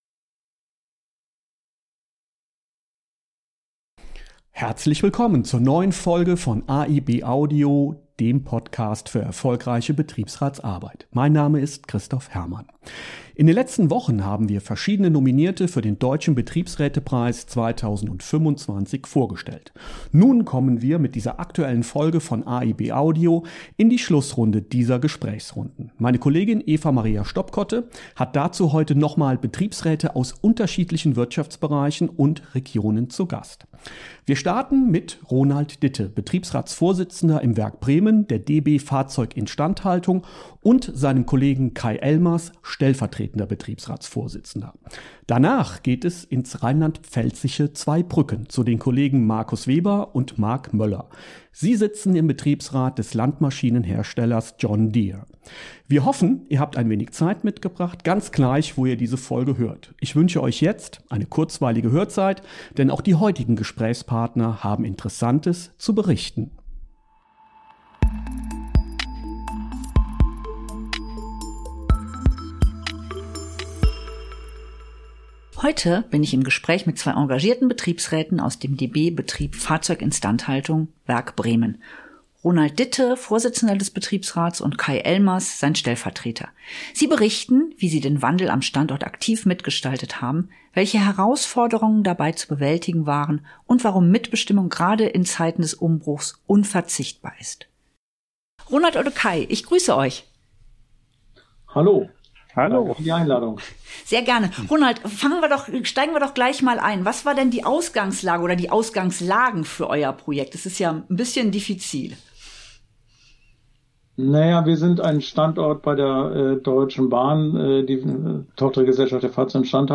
… continue reading 53 ตอน # Bildung # Bund-Verlag # Betriebsrat # Arbeitsrecht # Interviews # Recht # Für Betriebsräte